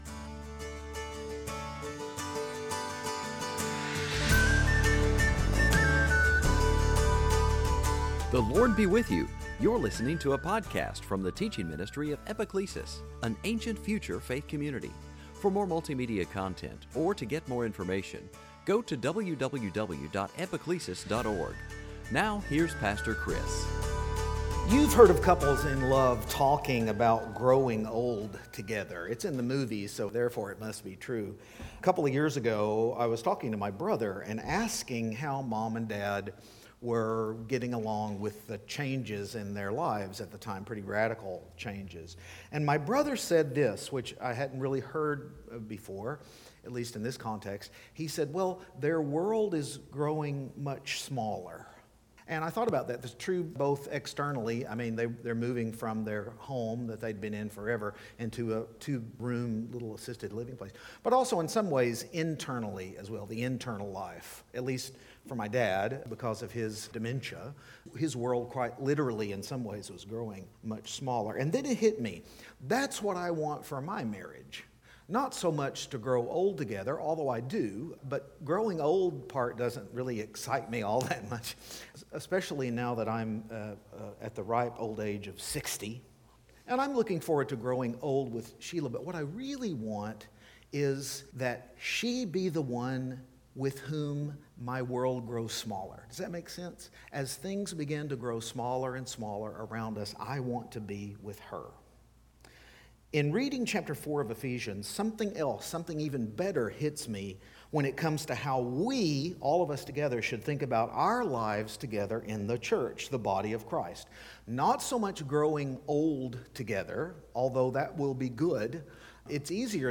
Sunday Teaching Passage